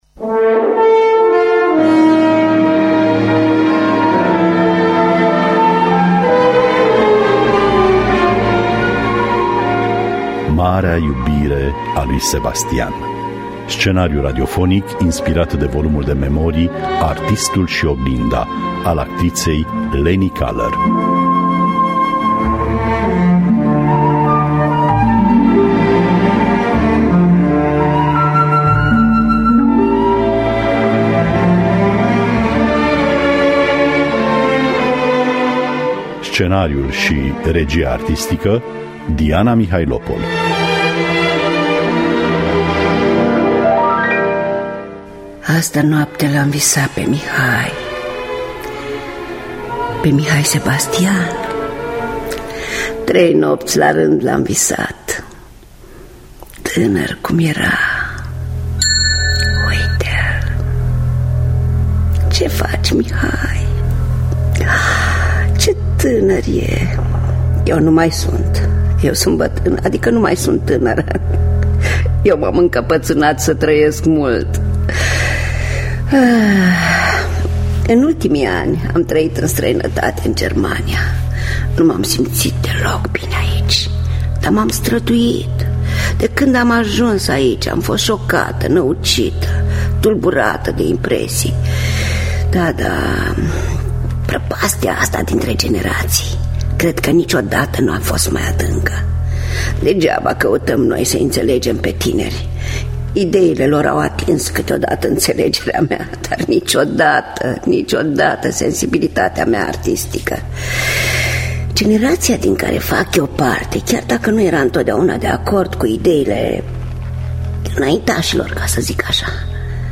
Scenariu radiofonic
înregistrare radiofonică din anul 1957
În rolurile Corina şi Ştefan Valeriu: Raluca Zamfirescu şi Radu Beligan.
În distribuţie Rodica Mandache și Marius Manole.